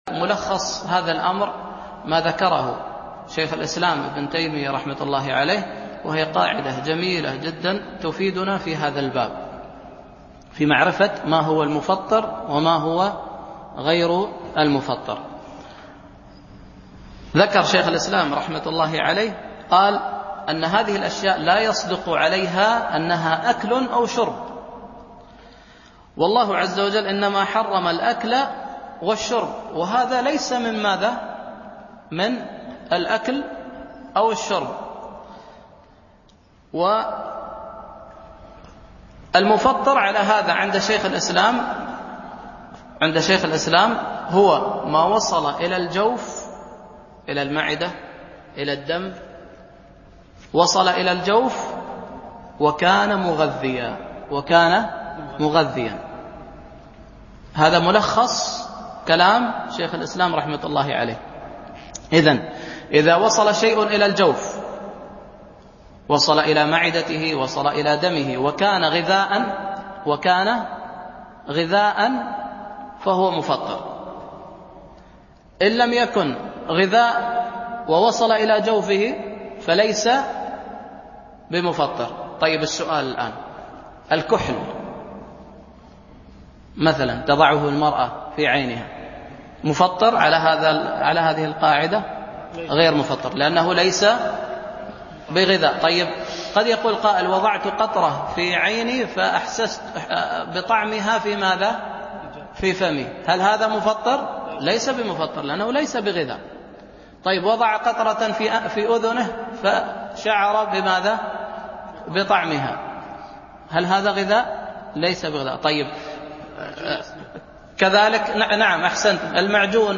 دروس مسجد عائشة